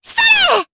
Dosarta-Vox_Attack2_jp.wav